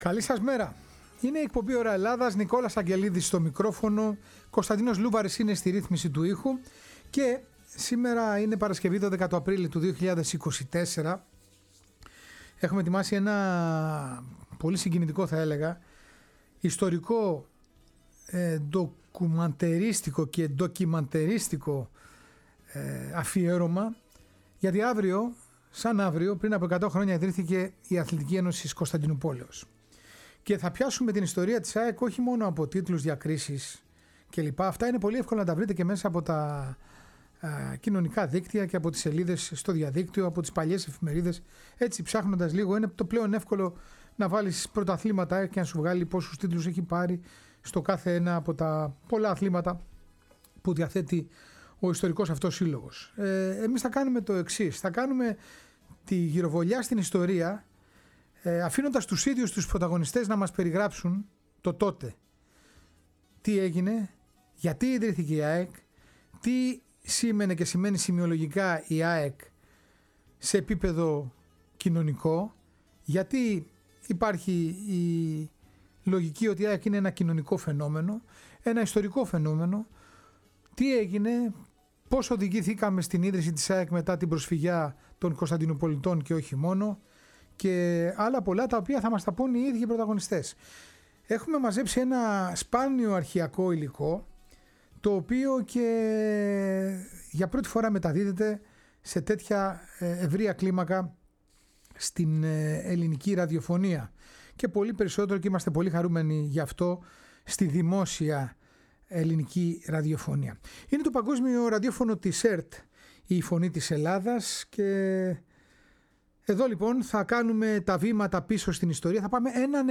Μυθικές μορφές μιλούν για την ίδρυση της ΑΕΚ και τους λόγους που έκαναν επιτακτική την ανάγκη δημιουργίας μιας ομάδας… που θα λέει την ιστορία!